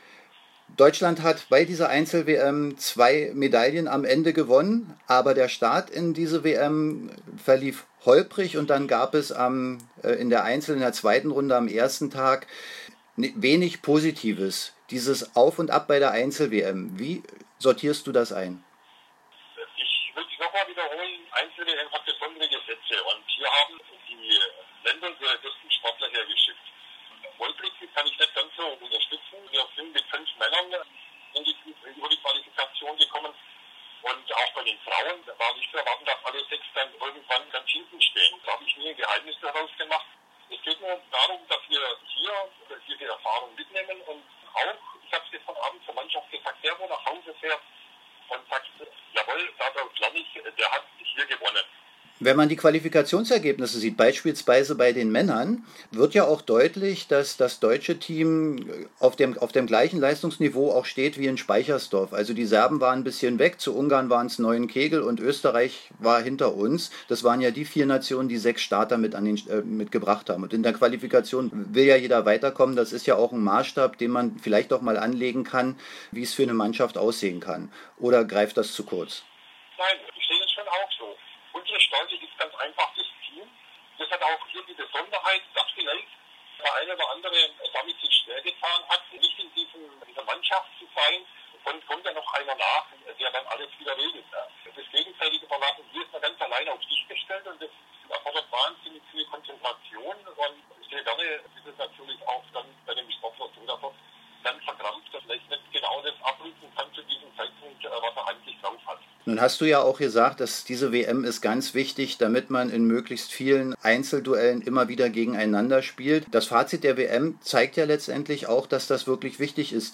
Während der VI. Einzel-WM sprachen wir im WM-Studio mit dem